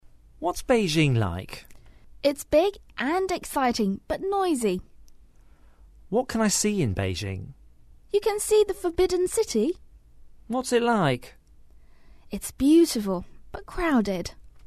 英语初学者口语对话第68集：北京怎么样？
english_17_dialogue_1.mp3